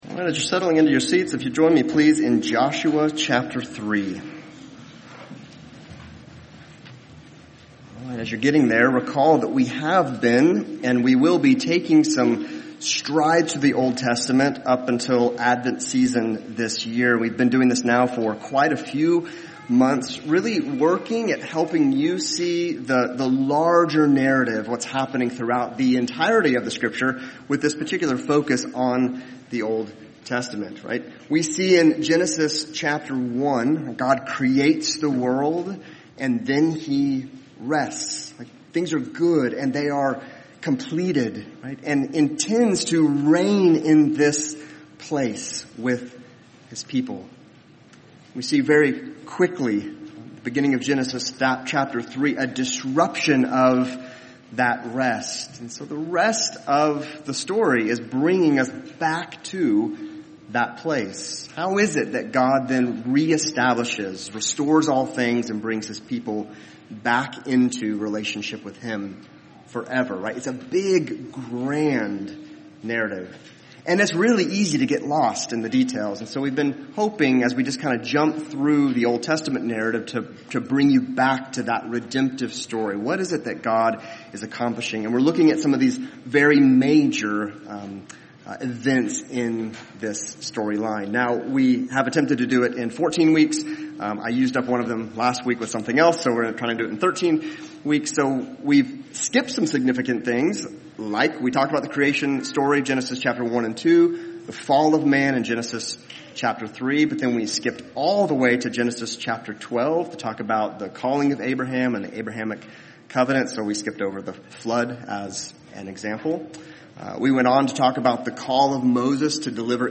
Joshua 3:1-17 Service Type: Sunday Topics